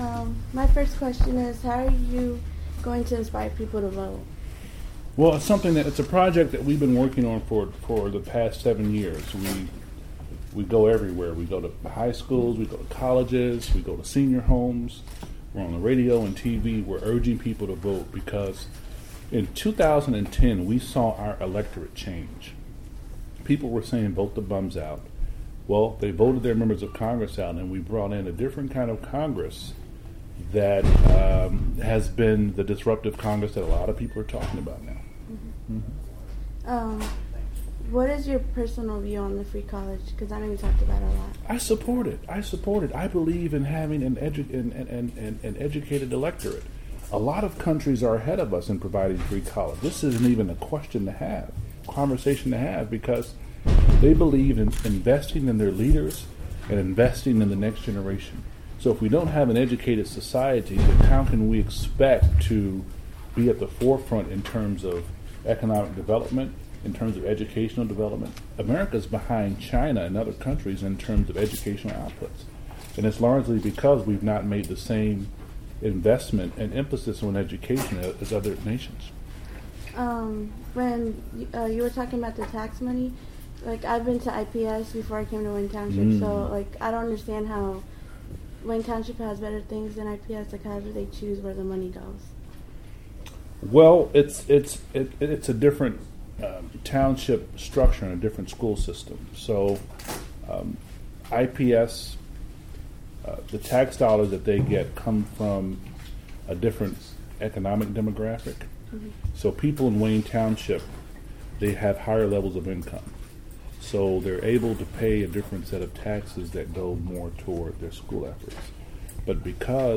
Congressman Andre Carson Interview